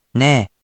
We’re going to show you the character, then you you can click the play button to hear QUIZBO™ sound it out for you.
In romaji, 「ね」 is transliterated as 「ne」which sounds like 「neigh」or 「nay」